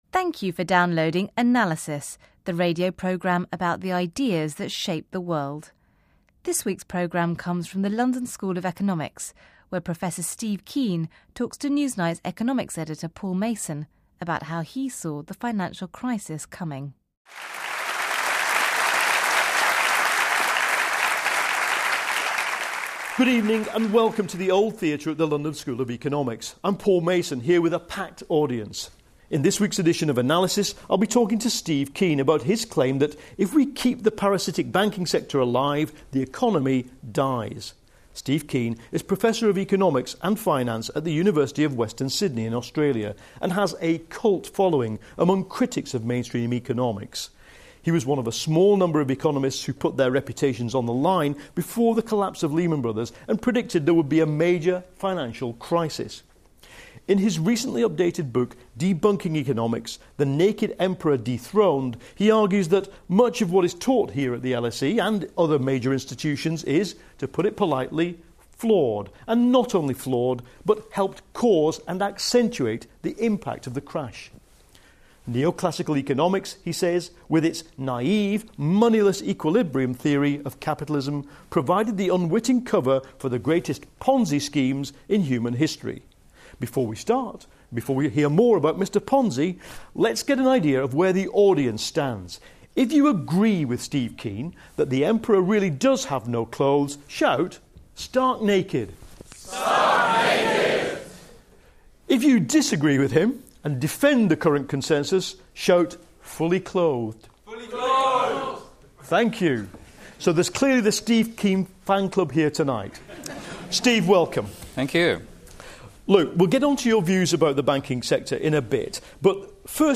Newsnight Economics Editor Paul Mason interviews the controversial economist Steve Keen before an audience at the London School of Economics. Keen was one of a small number of economists who predicted there would be a major financial crisis before the 2008 crash. He argues that if we keep the 'parasitic banking sector' alive the economy dies, and says that conventional economics provides an unwitting cover for 'the greatest ponzi schemes in history'.